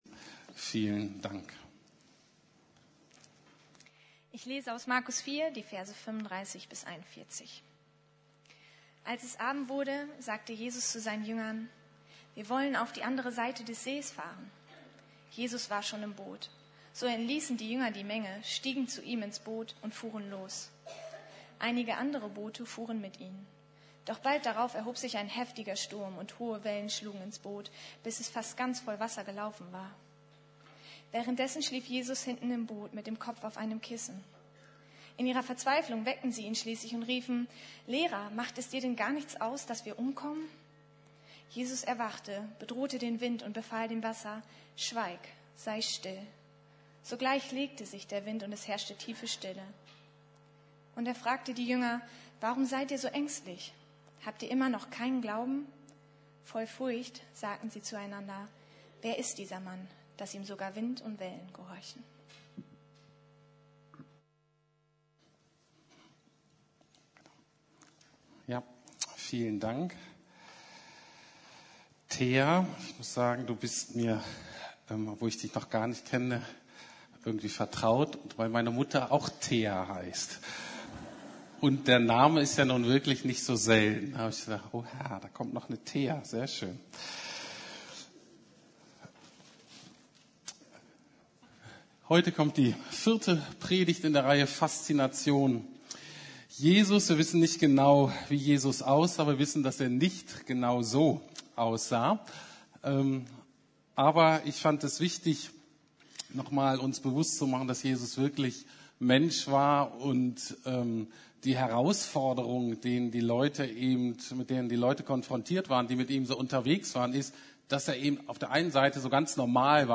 Fazination Jesus: Ein schrecklich liebender Gott ~ Predigten der LUKAS GEMEINDE Podcast